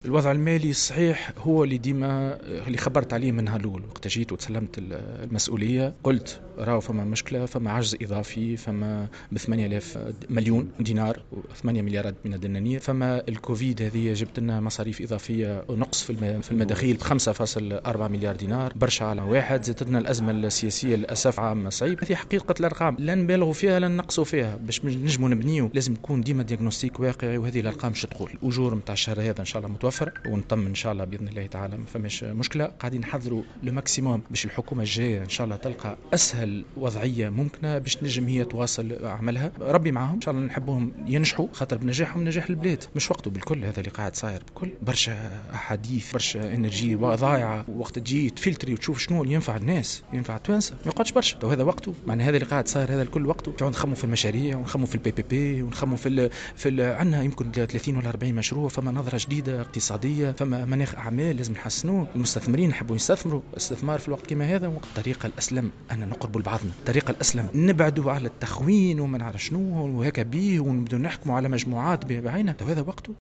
Le ministre des finances Nizar Yaïche, a annoncé, hier samedi, à Sfax, que les finances de l’Etat ont connu un énorme déficit à cause de la crise économique et politique, mais aussi, à cause de la crise générée par le covid.